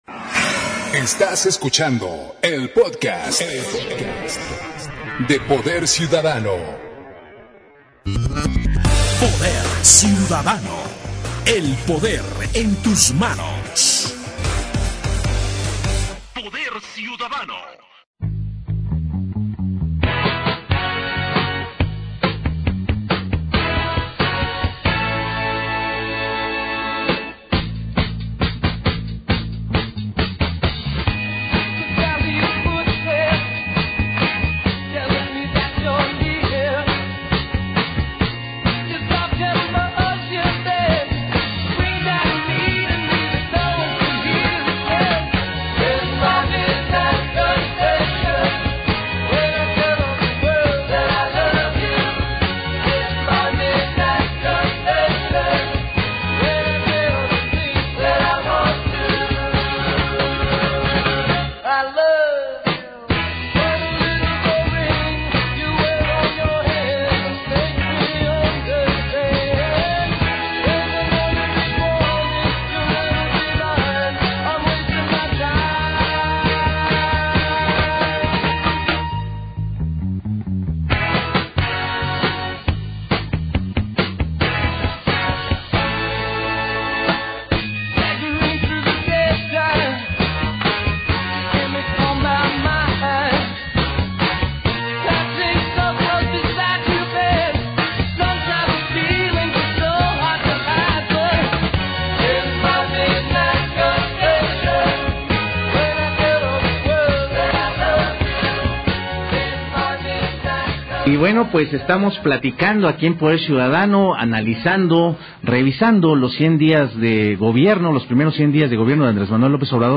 En entrevista para Poder Ciudadano